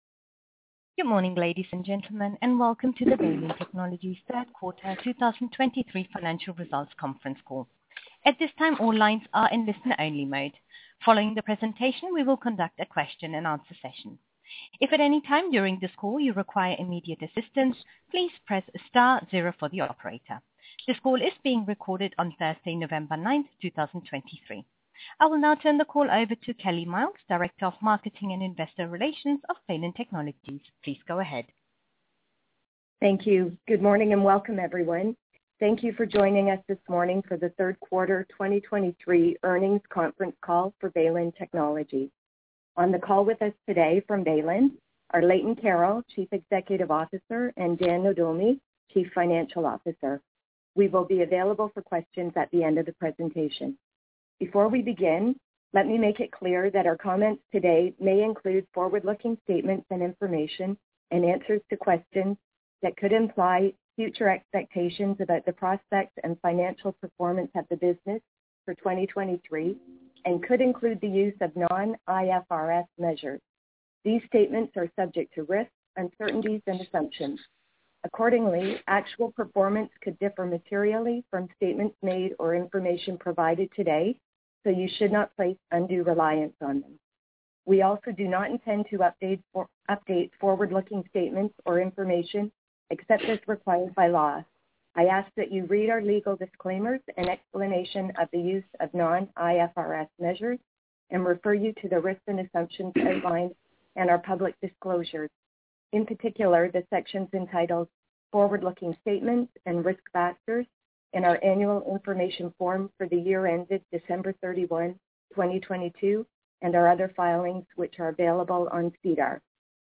Third Quarter 2023 Financial Results Conference Call